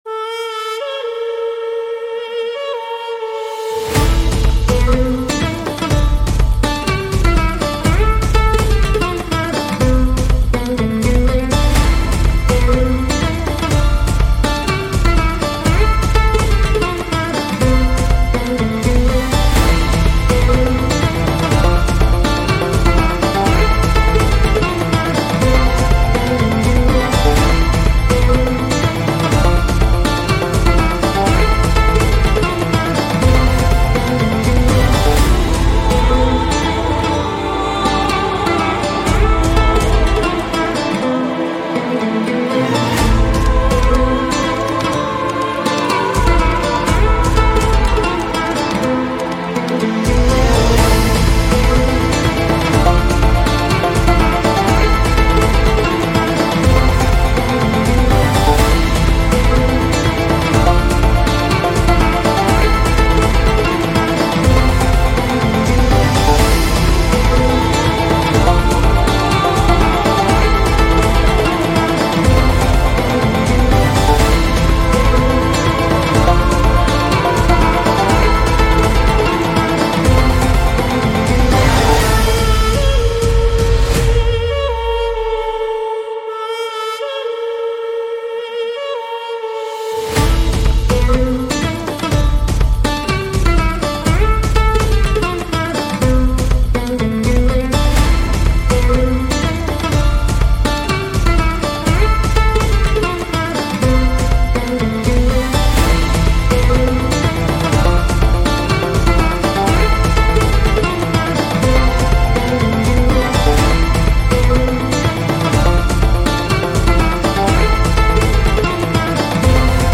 islamic instrumental music